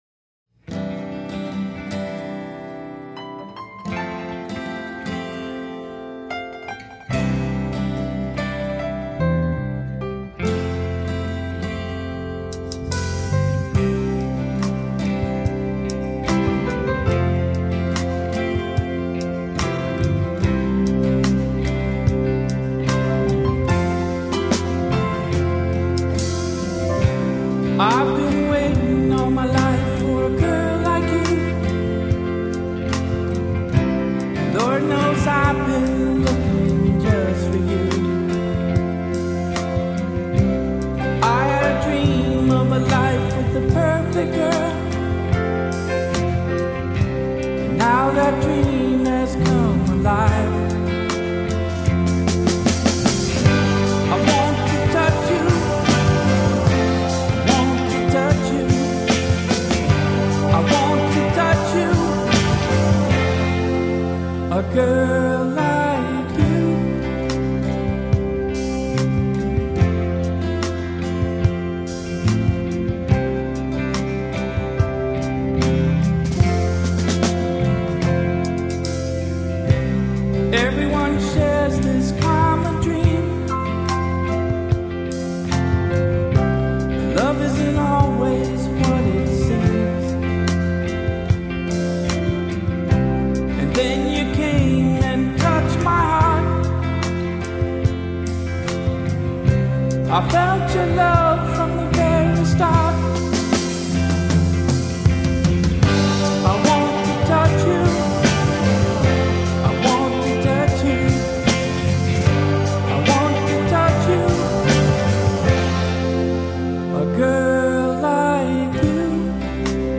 Guitar and Vocals
Bass and Vocal Harmony
Keyboards
Drums / Percussion
Acoustic Guitar